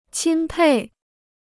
钦佩 (qīn pèi): to admire; to look up to.